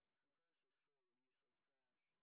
sp03_street_snr20.wav